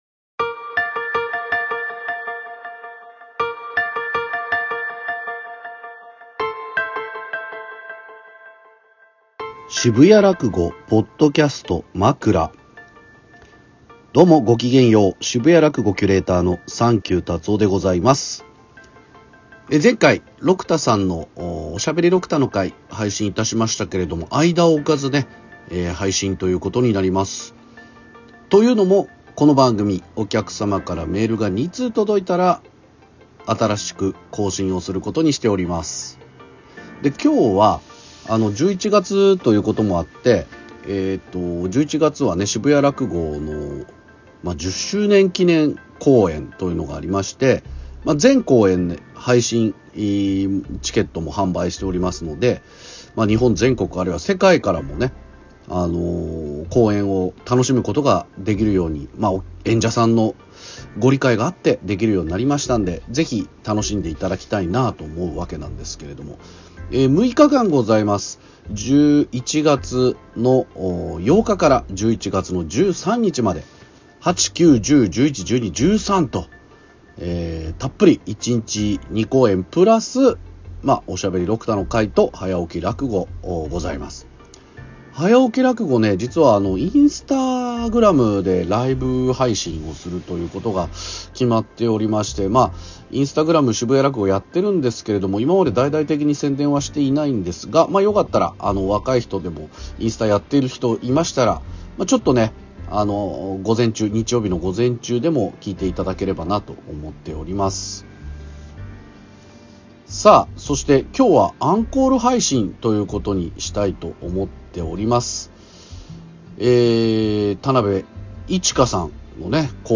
渋谷らくごのポッドキャスト渋谷のユーロライブで行われている「渋谷らくご」。そこで収録された「まくら」を配信中です。